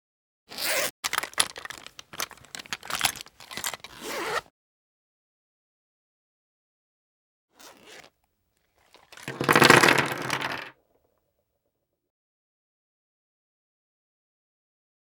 household
Make Up Bag Dump Contents Onto Wooden Table